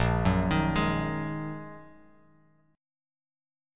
A#7sus4#5 chord